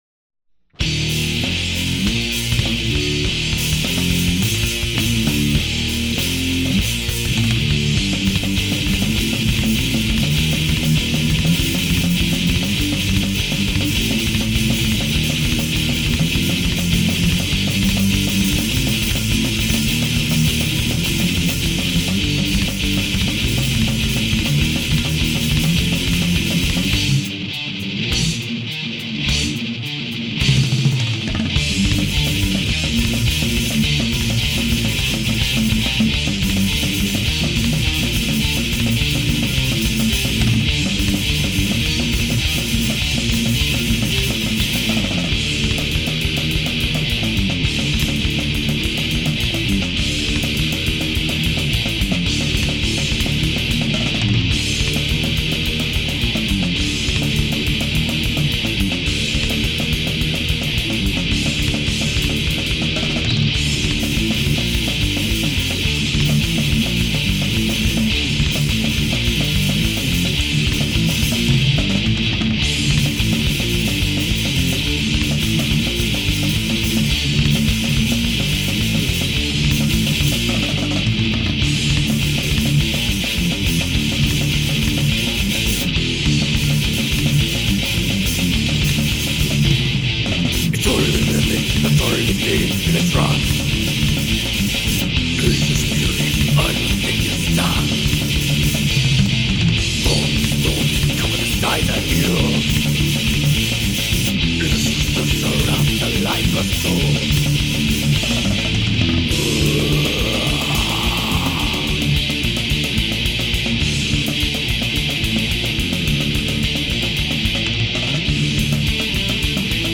Guitars/Vocals
Drums
Bass